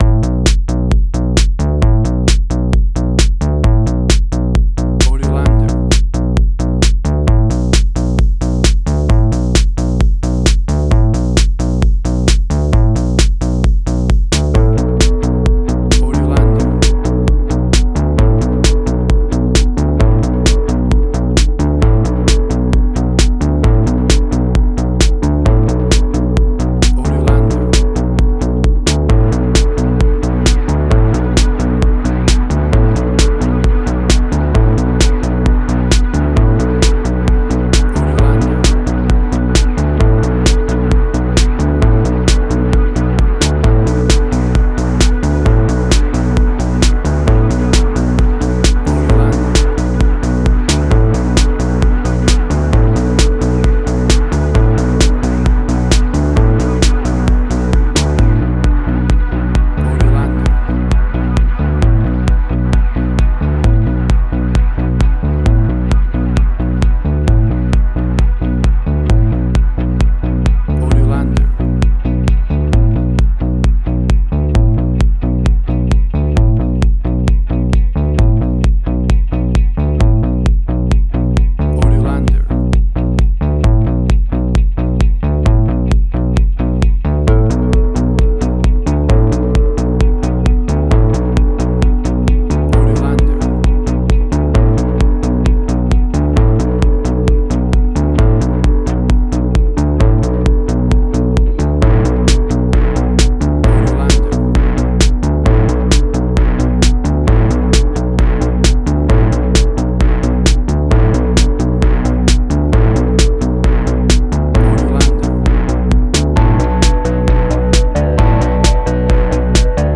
Future Retro Wave Similar Stranger Things New Wave.
WAV Sample Rate: 16-Bit stereo, 44.1 kHz
Tempo (BPM): 132